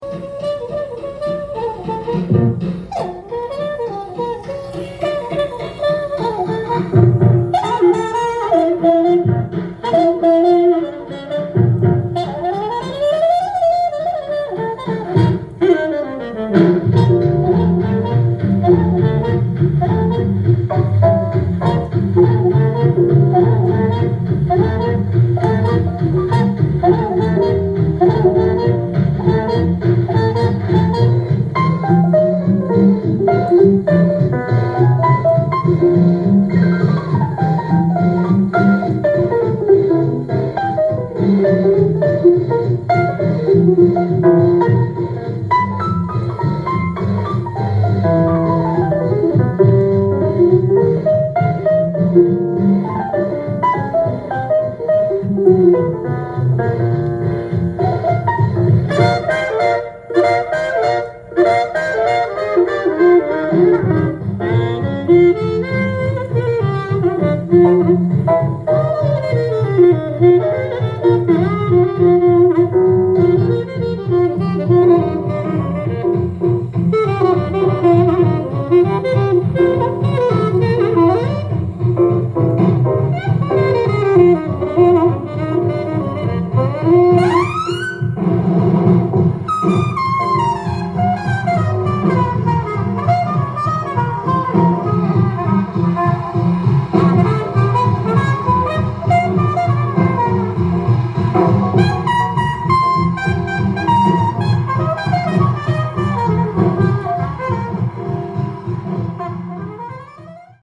ジャンル：JAZZ-ALL
店頭で録音した音源の為、多少の外部音や音質の悪さはございますが、サンプルとしてご視聴ください。
豪華なメンバーの自由気ままな演奏が繰り広げられています。